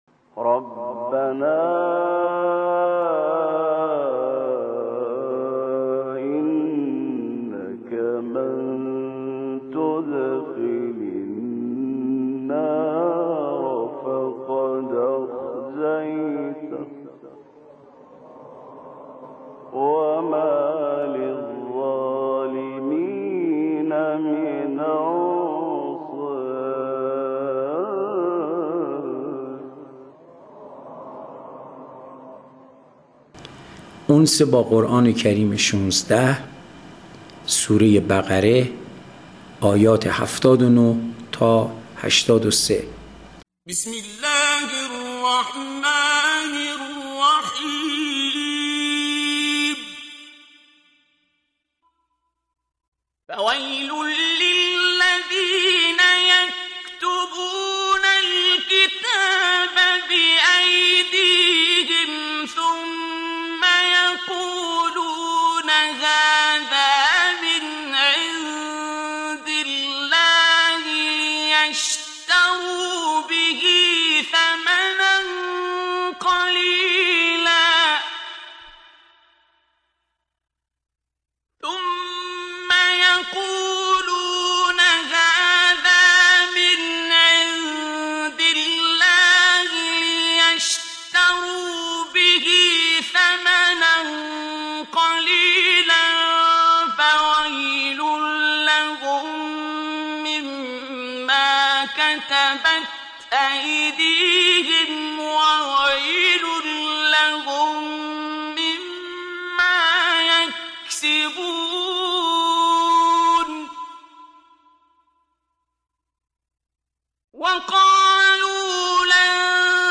قرائت آیات آیات 80 الی 83 سوره بقره